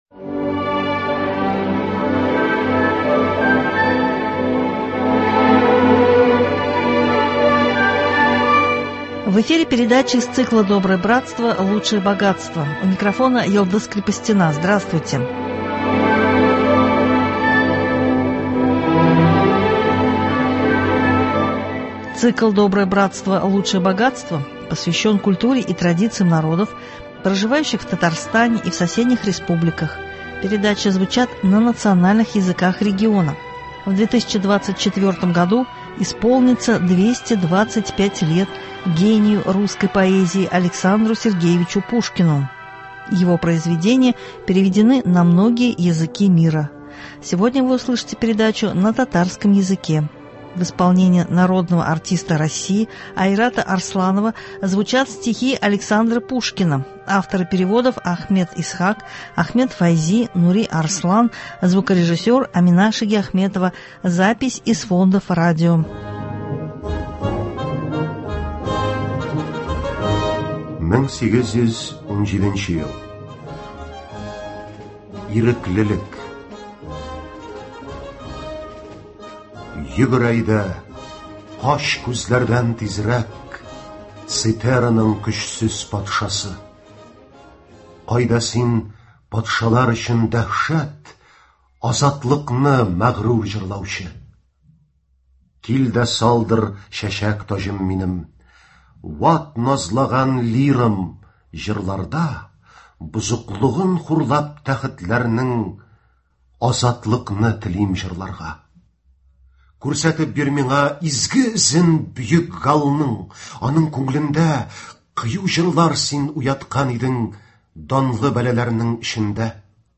В исполнении народного артиста России Айрата Арсланова звучат стихи Александра Пушкина